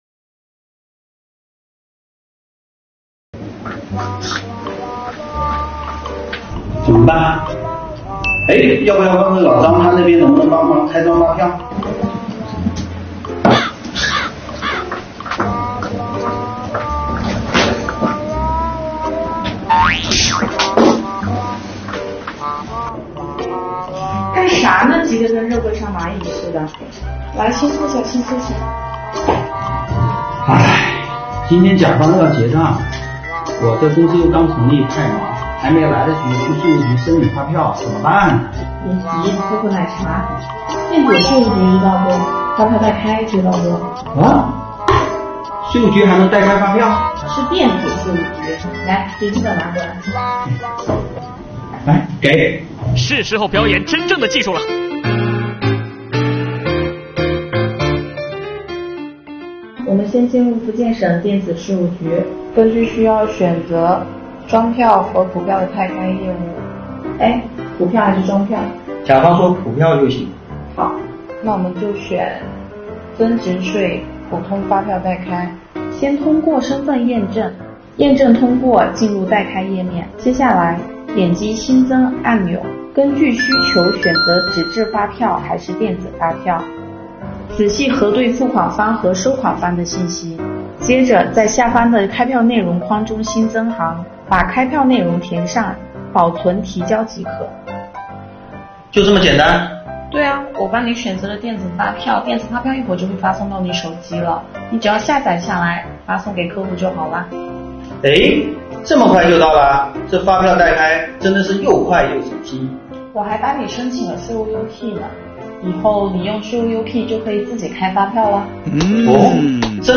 作品节奏和背景音乐搭配和谐，运镜舒适，引发受众的观看兴趣。